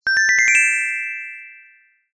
Звуки феи
Пинг волшебной палочки, звон серебряного колокольчика, фея-крестная тринадцать